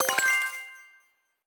Special & Powerup (25).wav